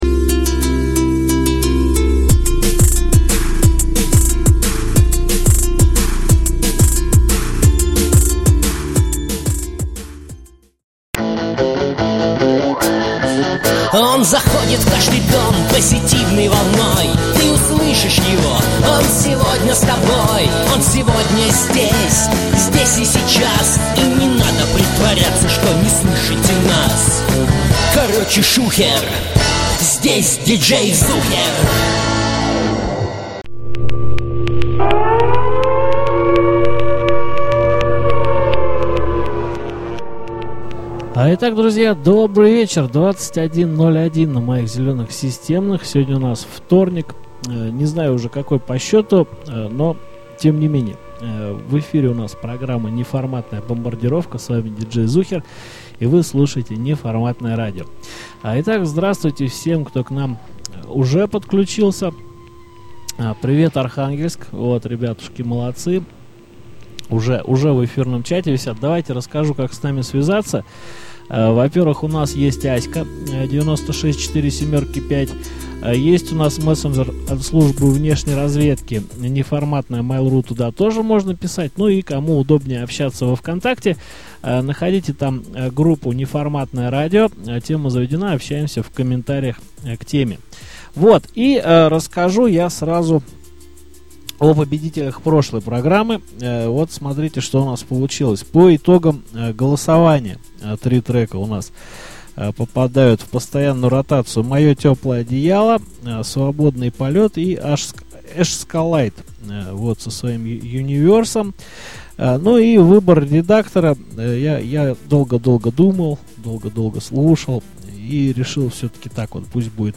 меланхолично-осенняя